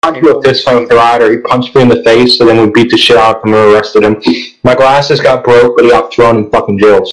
(T)(Army)Riot fight